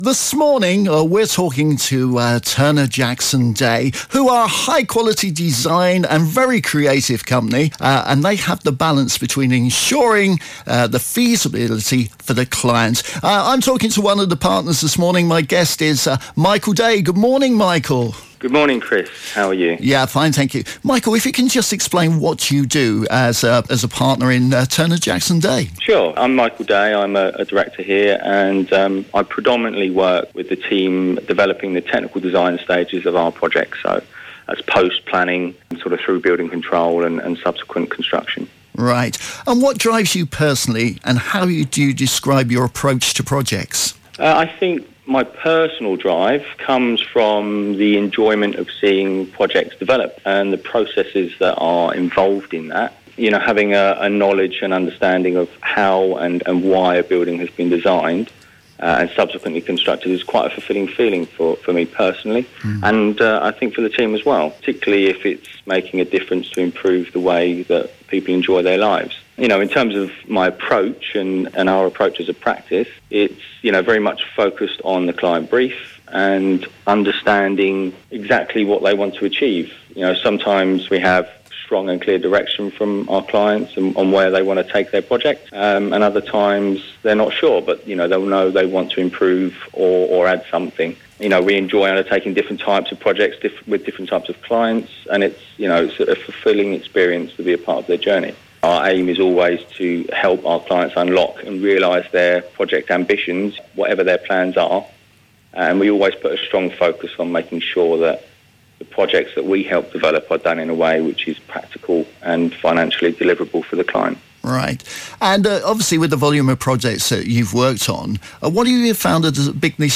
Radio interview with Director